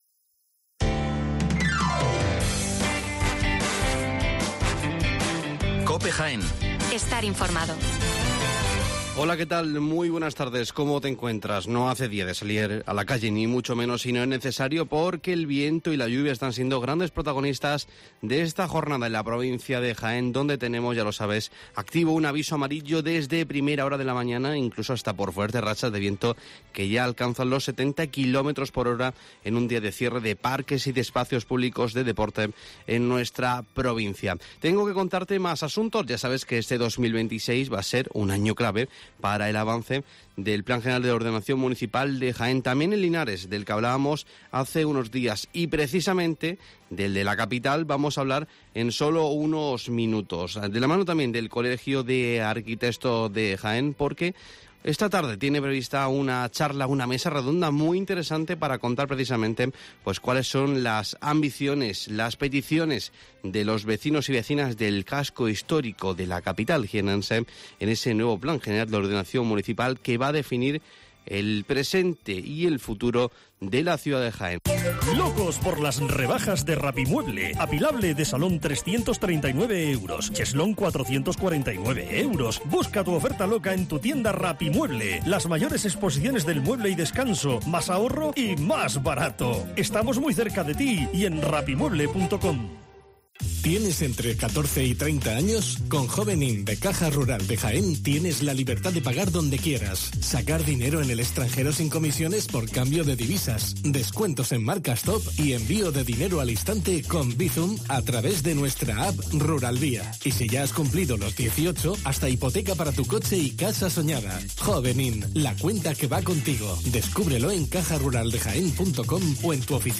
La Voz de la Arquitectura «Mesa Redonda ‘Diálogo sobre el casco histórico y el planeamiento urbanístico’».